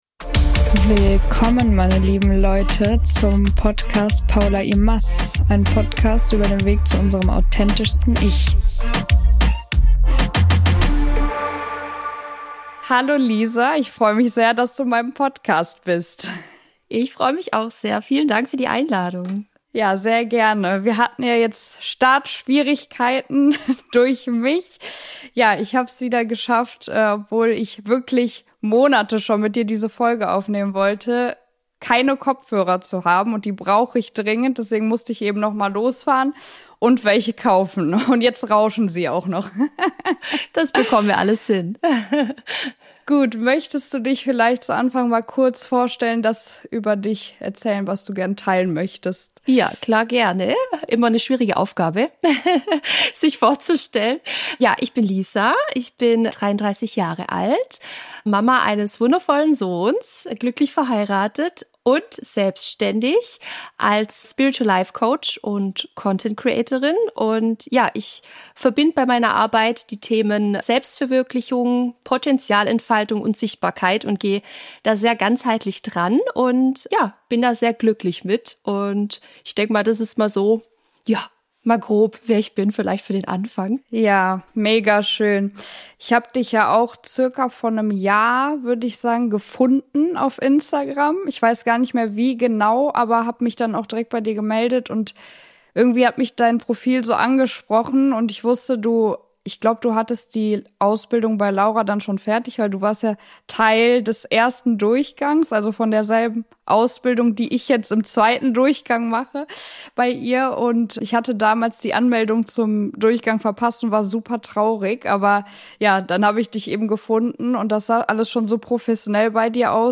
Ein Gespräch über Erinnerungen und Momente, die bleiben.